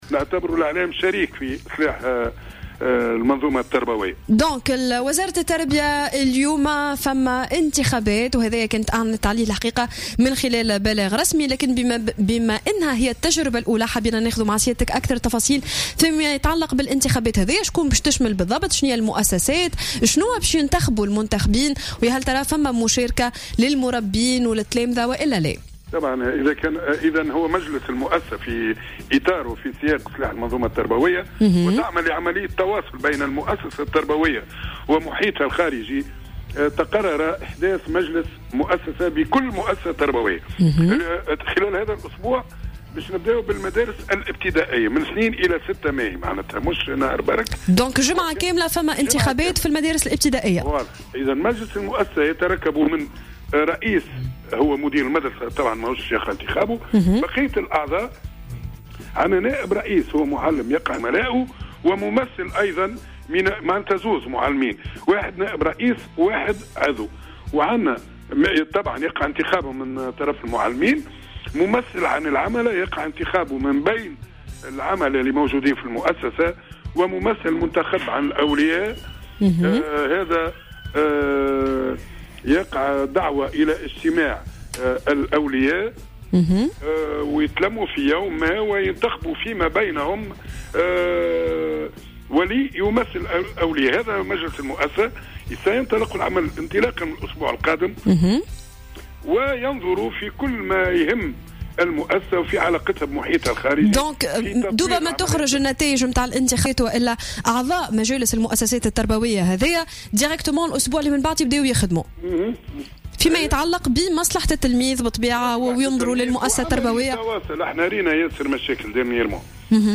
في تصريح للجوهرة أف أم في برنامج صباح الورد